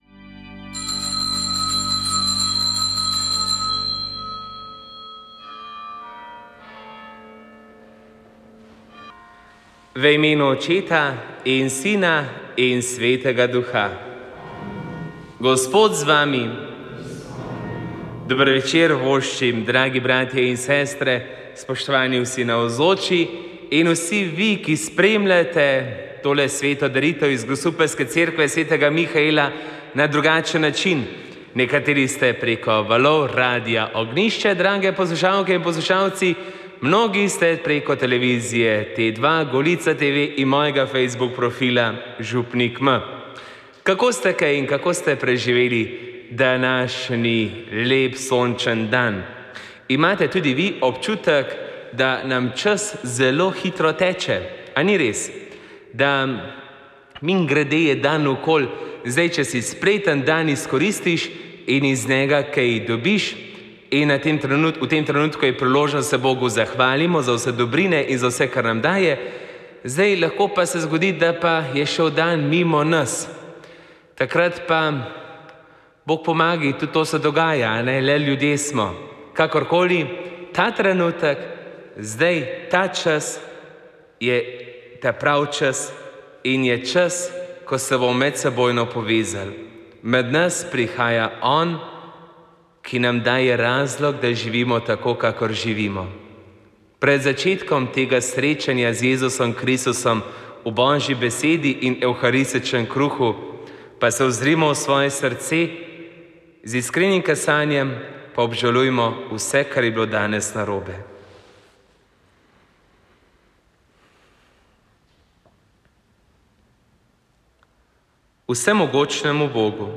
Sveta maša
Sv. maša iz cerkve sv. Mihaela v Grosuplju 22. 4.